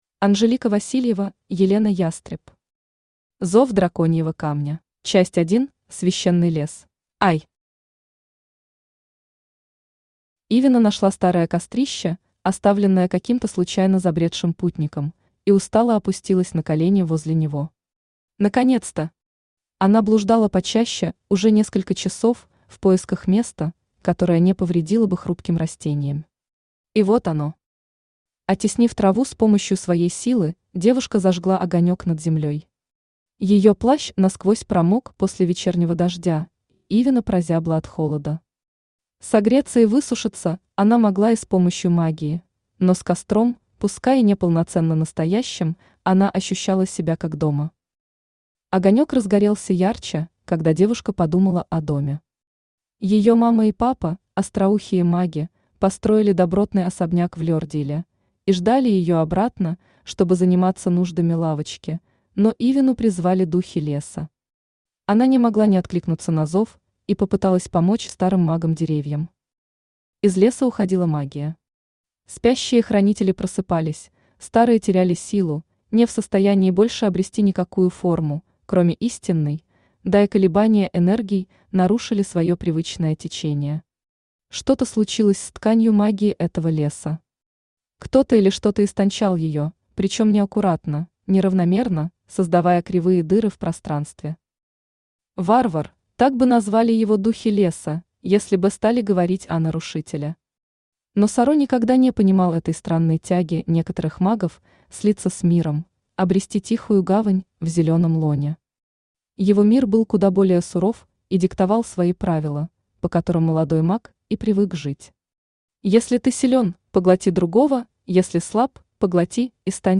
Аудиокнига Зов Драконьего Камня | Библиотека аудиокниг
Aудиокнига Зов Драконьего Камня Автор Анжелика Александровна Васильева Читает аудиокнигу Авточтец ЛитРес.